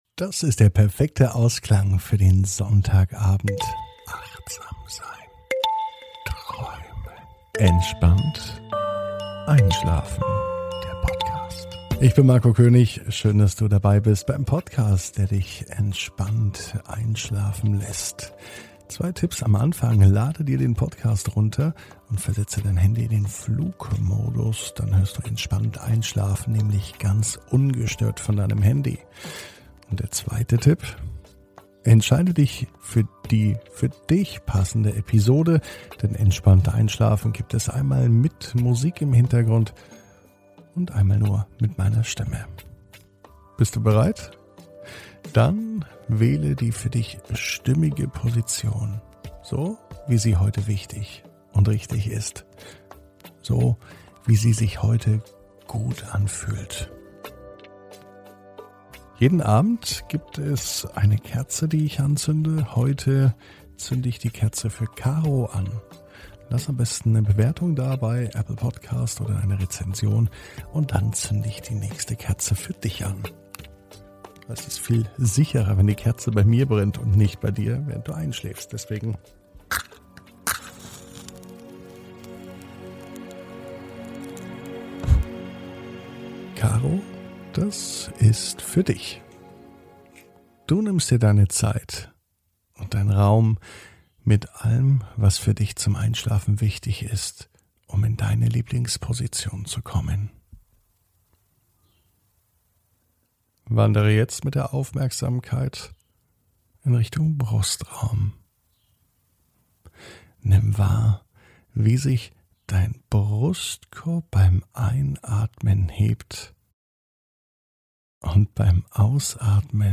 (ohne Musik) Entspannt einschlafen am Sonntag, 06.06.21 ~ Entspannt einschlafen - Meditation & Achtsamkeit für die Nacht Podcast